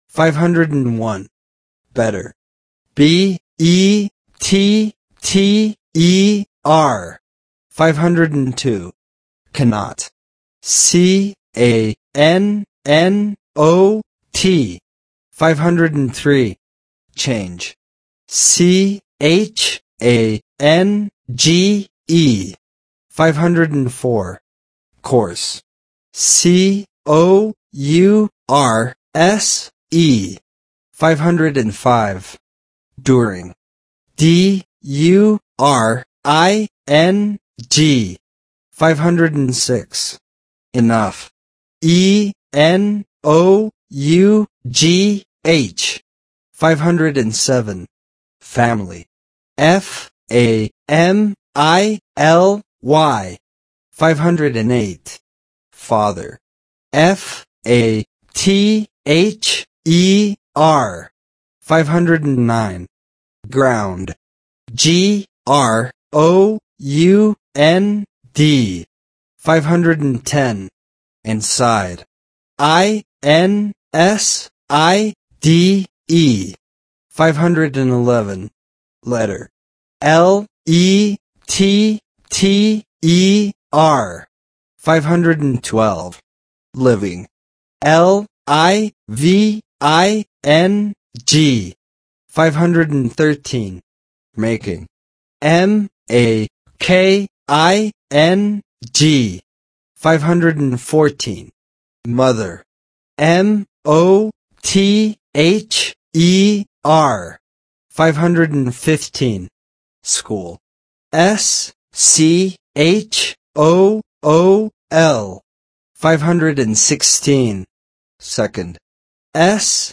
Spelling Exercises
501 – 550 Listen and Repeat